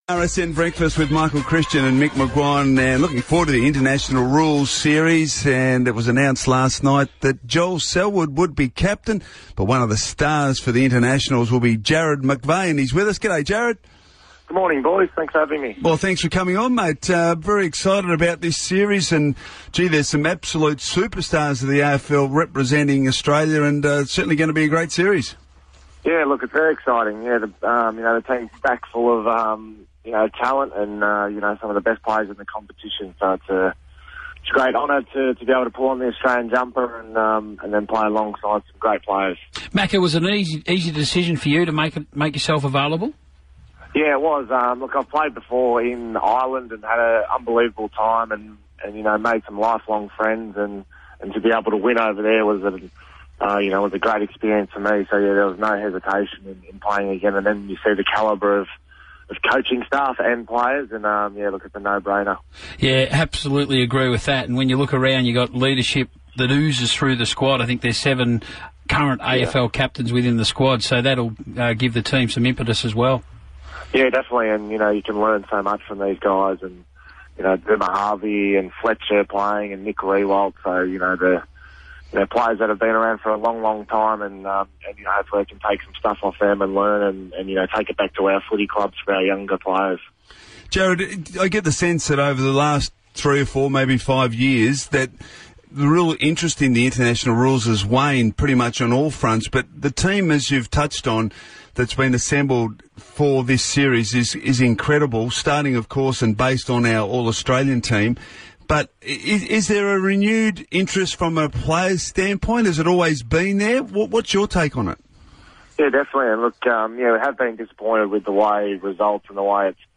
Sydney Swans co-captain Jarrad McVeigh appeared on Radio Sports Nation's breakfast program on Tuesday November 18, 2014 ahead of the International Rules Series